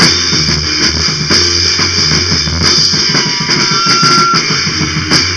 playing drums togehter
sounds techno like